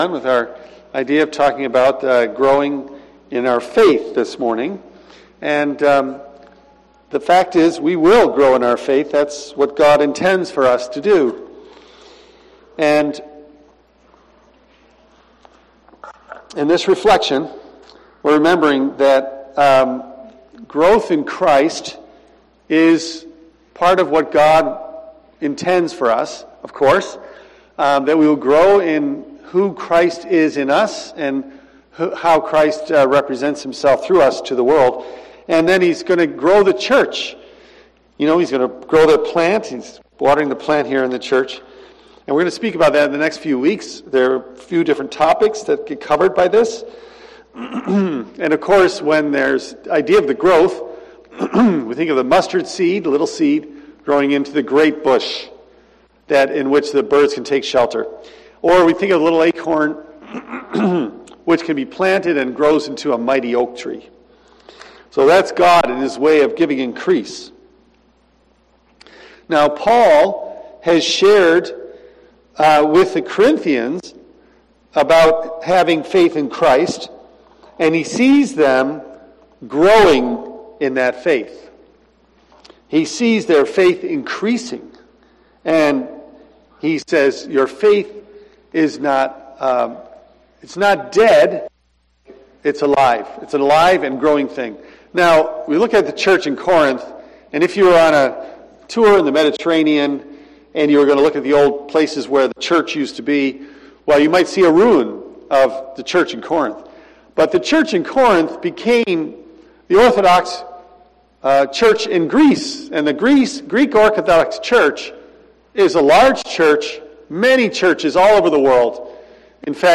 I am going to preach on growth in Christ and growth in the church for the next few weeks. Paul has given the Corinthians their faith in Christ and sees them growing in that.